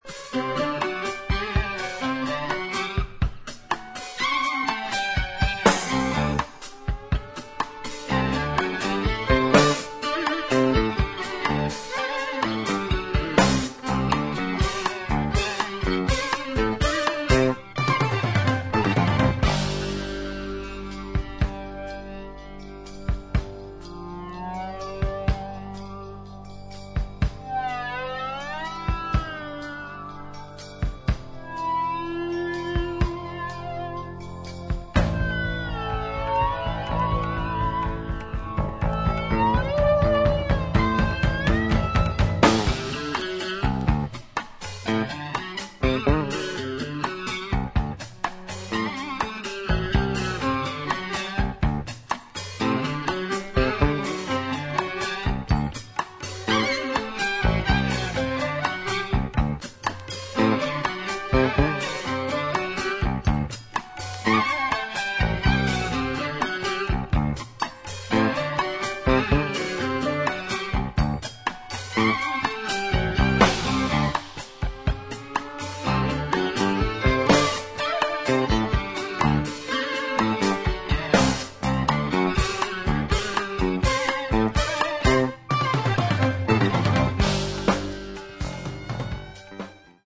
ロックの要素も取り入れた民族系ジャズ・グループ